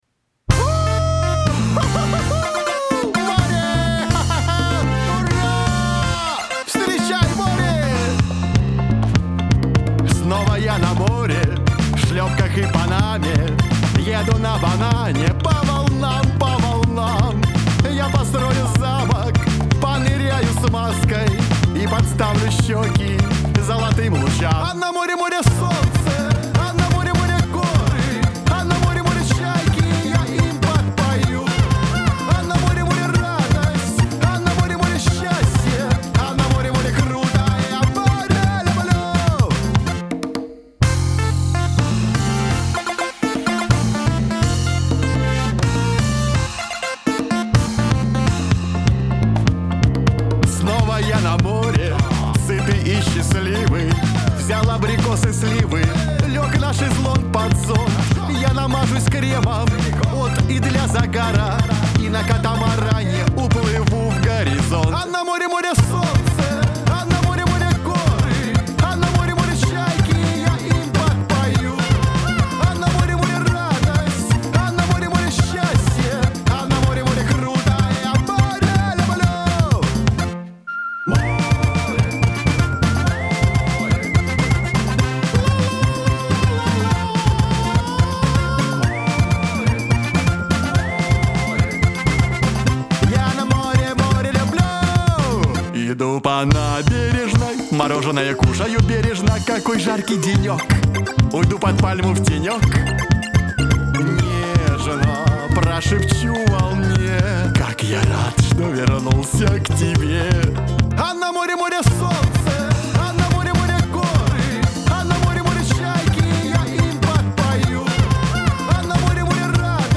дуэт
Веселая музыка